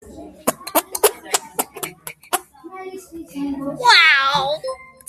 Fart Power Sound Effect Download: Instant Soundboard Button